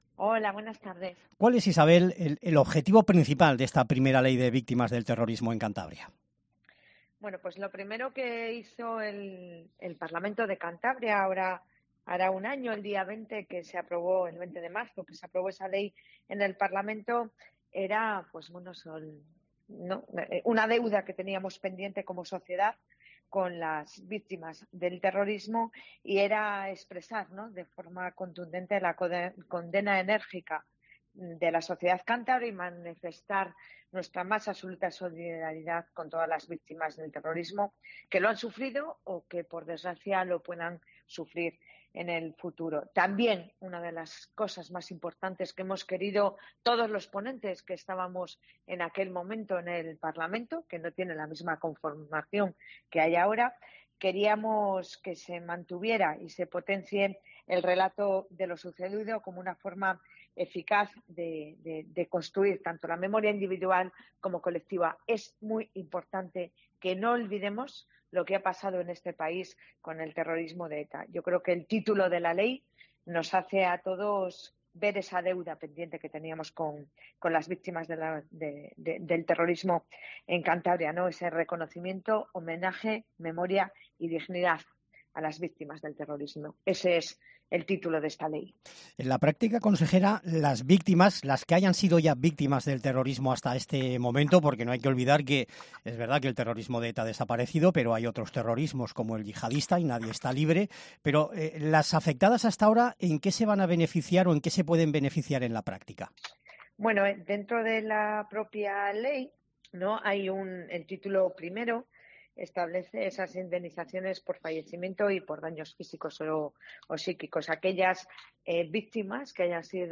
Isabel Urrutia, consejera de Presidencia, analiza la ley de víctimas del terrorismo